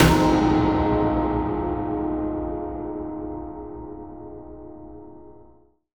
Synth Impact 24.wav